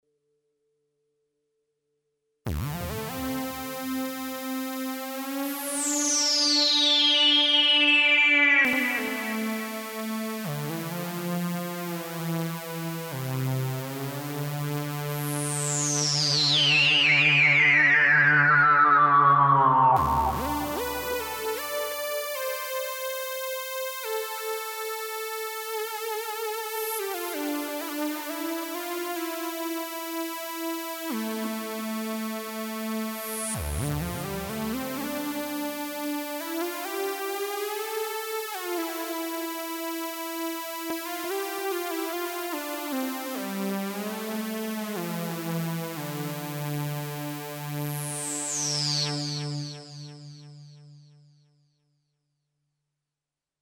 Per il terzo esempio sonoro (XioSynth03.mp3) ho scelto un semplice Lead Synth per evidenziare la pulizia sonora e la limpidezza del filtro.